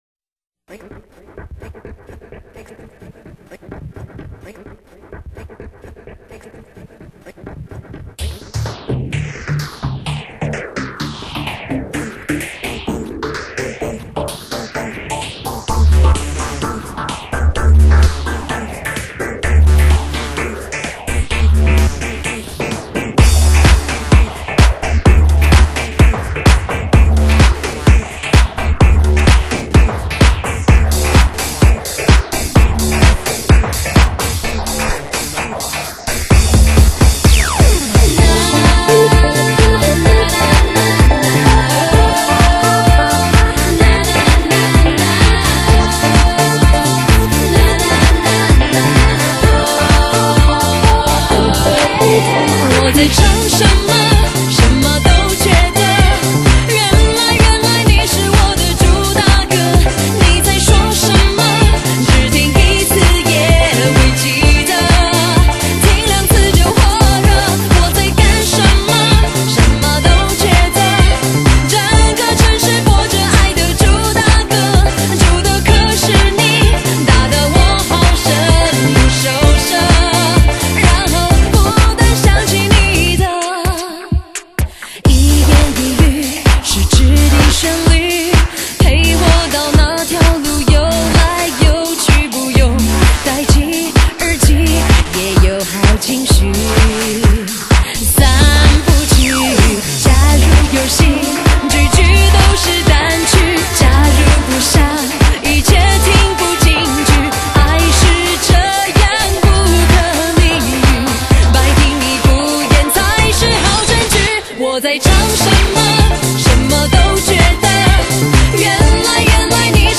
因为你…21世纪的华语乐坛多了一个令人惊叹的“魅惑中低音”